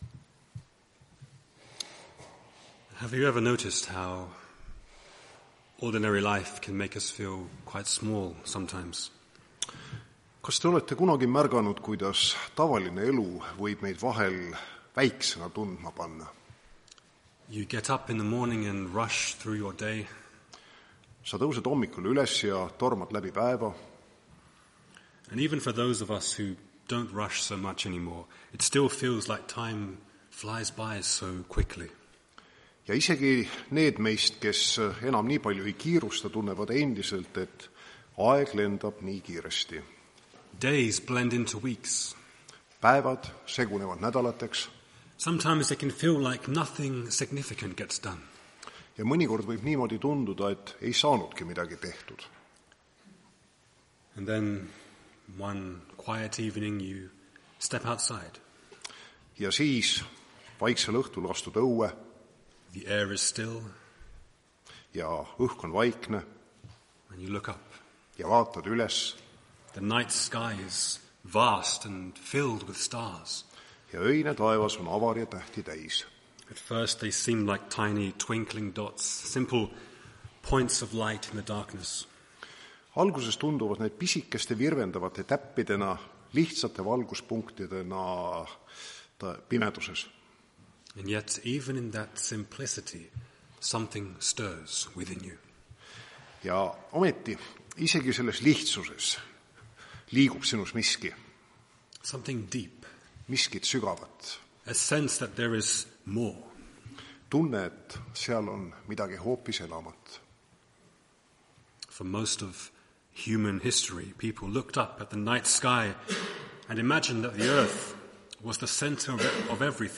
Tartu adventkoguduse 14.02.2026 teenistuse jutluse helisalvestis.
Jutlused